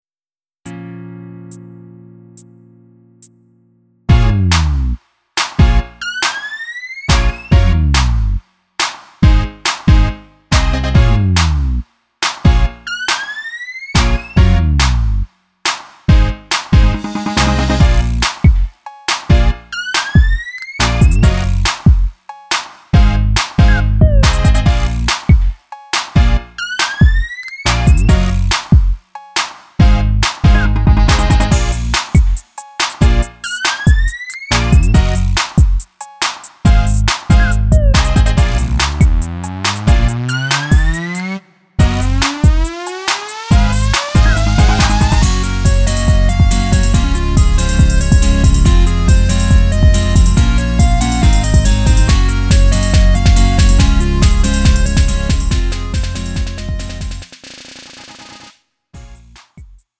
음정 -1키 2:53
장르 구분 Lite MR